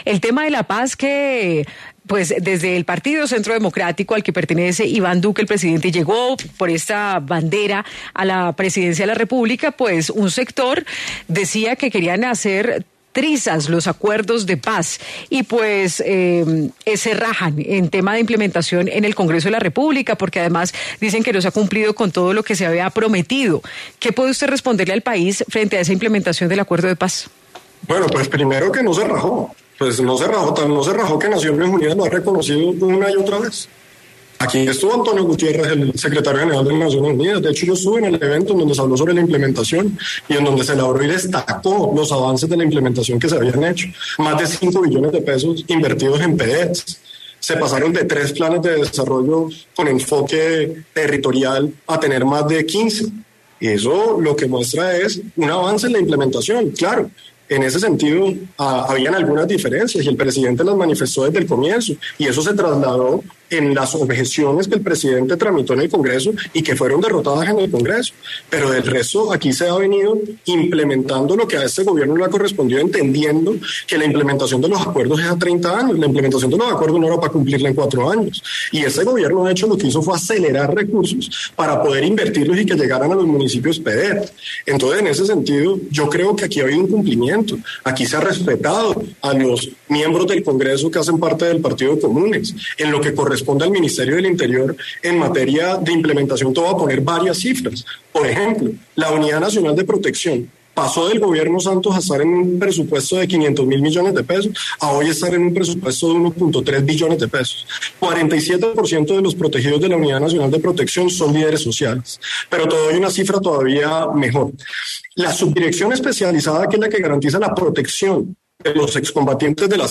Daniel Palacios, el ministro del Interior, habló en Sigue La W sobre las críticas de quienes decían que querían “hacer trizas el Acuerdo de Paz” en el Gobierno Duque.